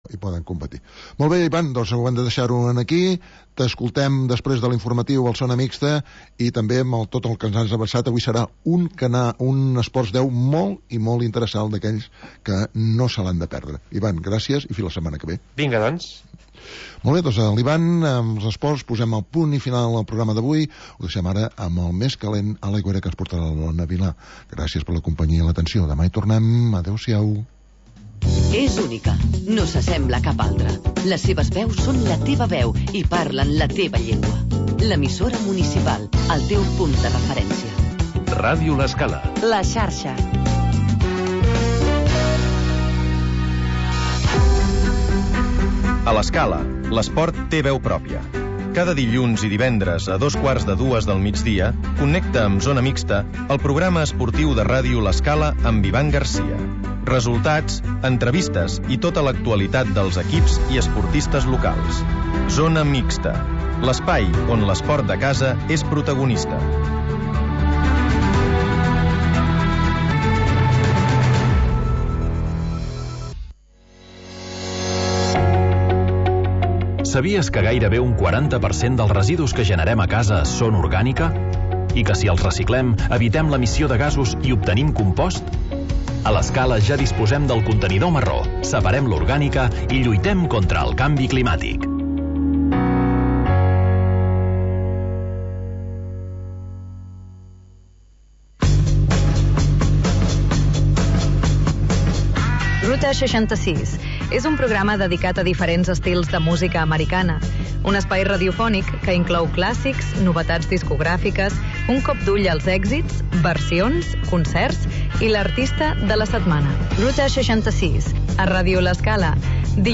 Magazín d'entreteniment